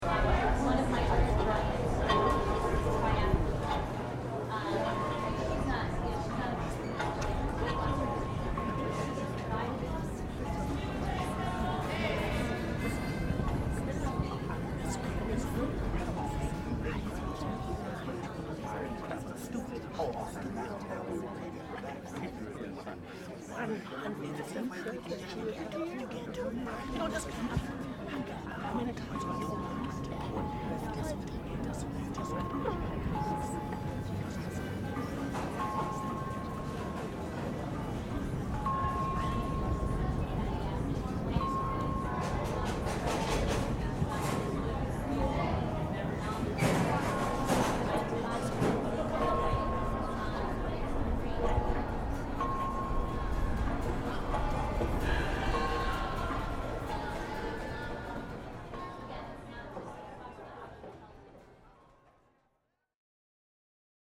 Sound montage (places, people, and devices)